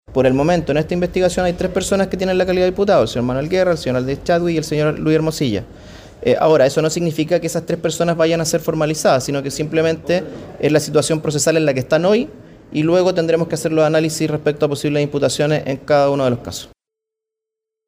El fiscal regional de Arica, Mario Carrera, recalcó que la investigación no se encuentra cerrada y que existen diligencias pendientes que podrían ampliar el objeto del proceso, incluso respecto de hechos ocurridos con posterioridad al término del cargo de Guerra.